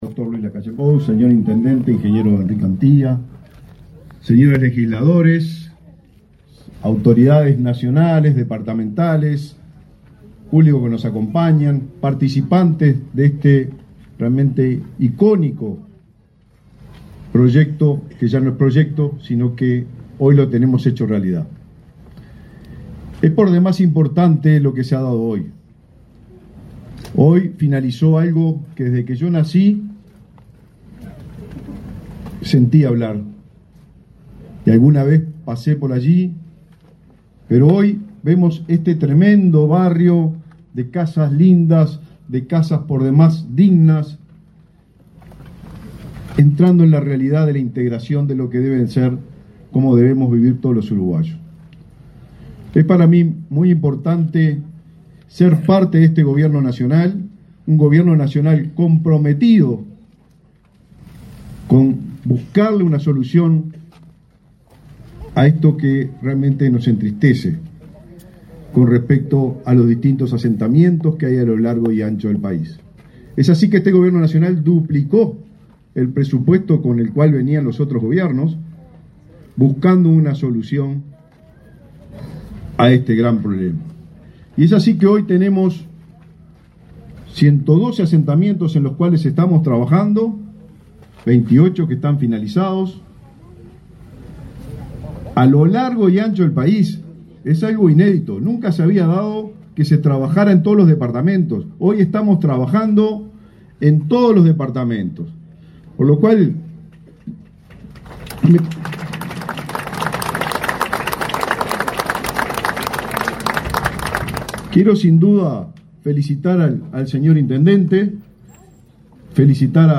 Palabras del titular del MVOT, Raúl Lozano
El presidente de la República, Luis Lacalle Pou, acompañó al intendente de Maldonado, Enrique Antía, a la demolición de la última casa del asentamiento Kennedy, en Maldonado, con lo que se culmina el realojo de 530 familias que vivían allí. En la oportunidad, el titular del Ministerio de Vivienda y Ordenamiento Territorial (MVOT), Raúl Lozano, realizó declaraciones.